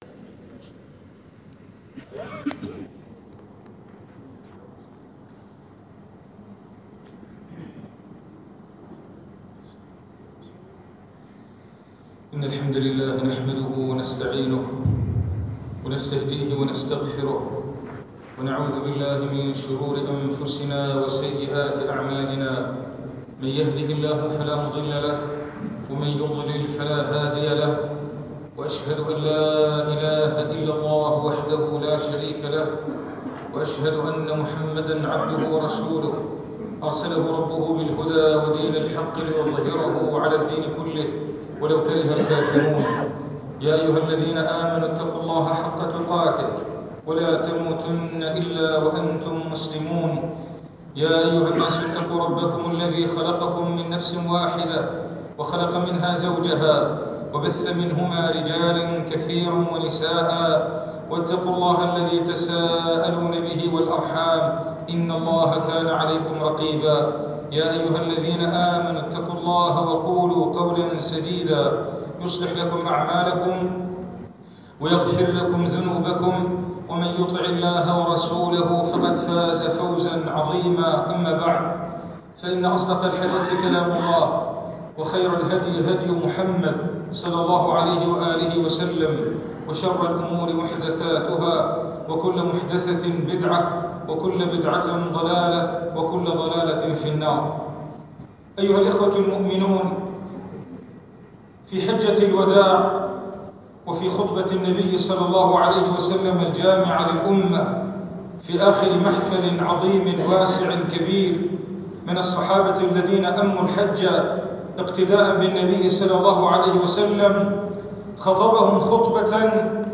المكان : المسجد البحري